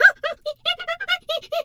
hyena_laugh_04.wav